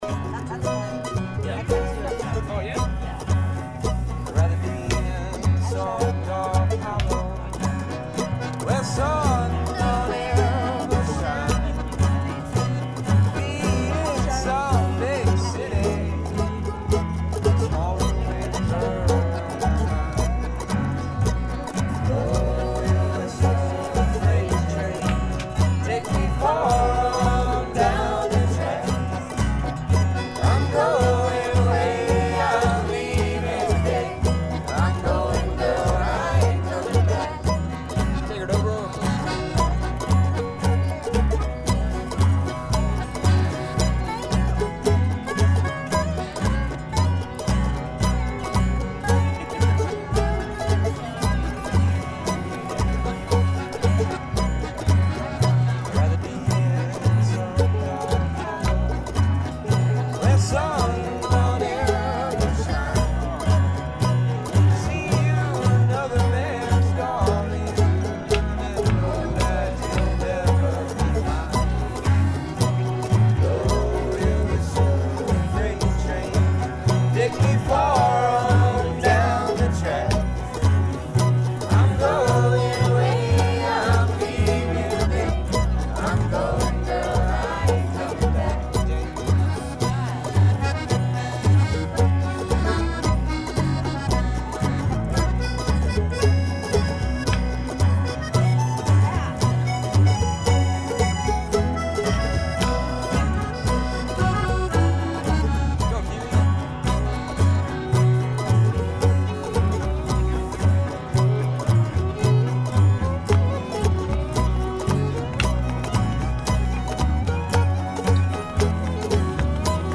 We crossed paths and setup the jam right where we met at the crossroads by the "top of the world" bus.
The crowd began to form and before long we were stirring up folk roots with the aid of the players in the campground!
and many others on banjo, mandolin, fiddle, guitars, and so on...Please email with any more information you may have to add...